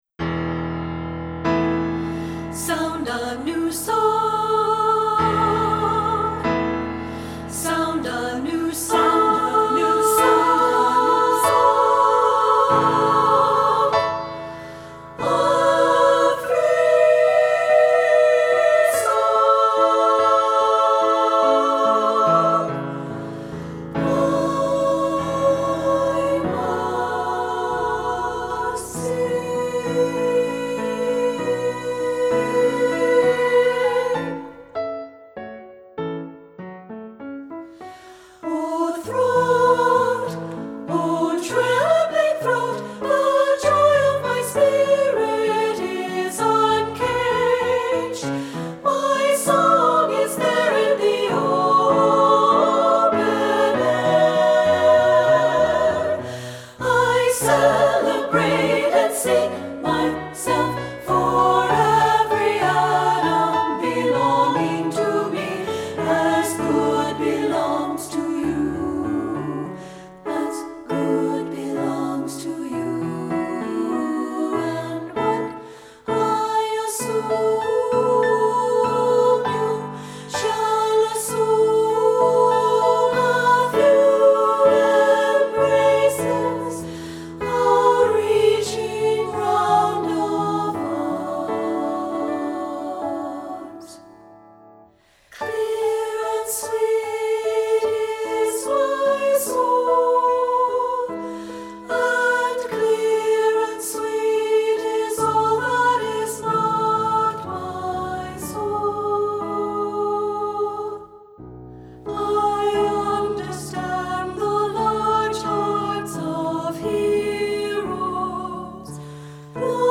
• Soprano 1
• Soprano 2
• Alto
• Piano
Studio Recording
Ensemble: Treble Chorus
Accompanied: Accompanied Chorus